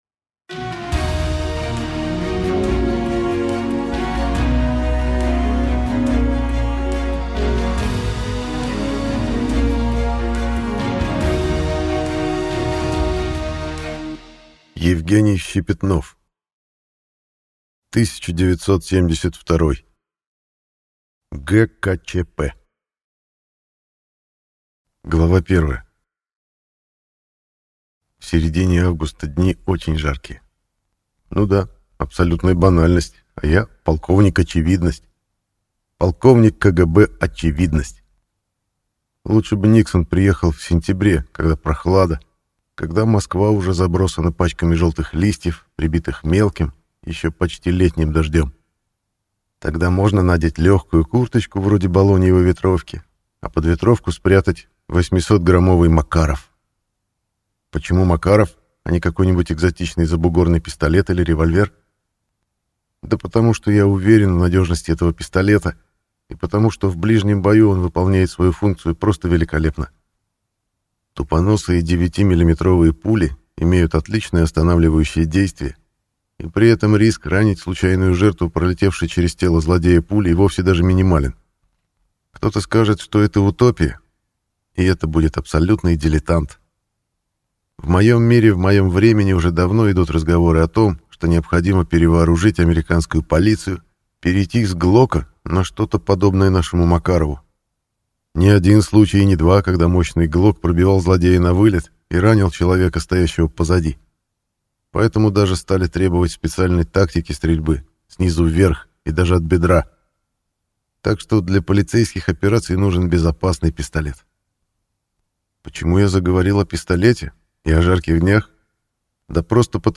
Аудиокнига 1972. ГКЧП | Библиотека аудиокниг